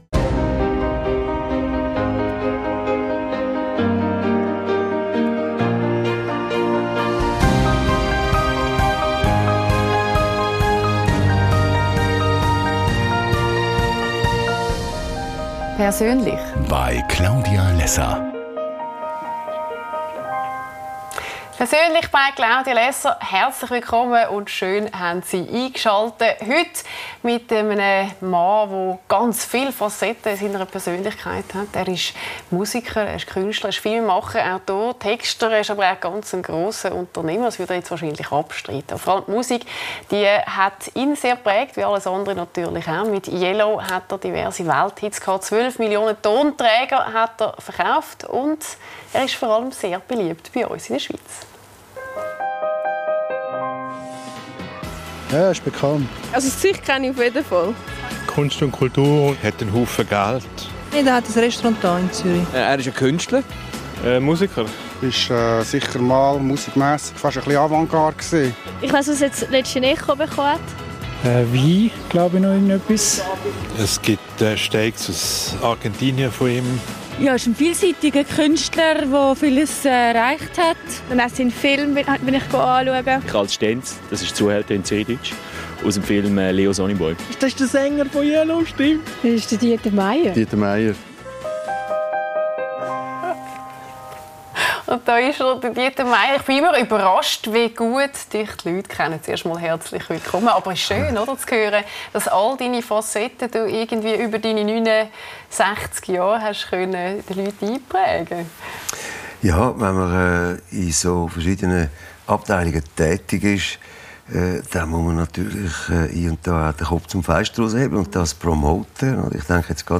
Lässer Classics zeigt die besten Talks aus den letzten zehn Jahren mit Claudia Lässer. In dieser Folge: Dieter Meier.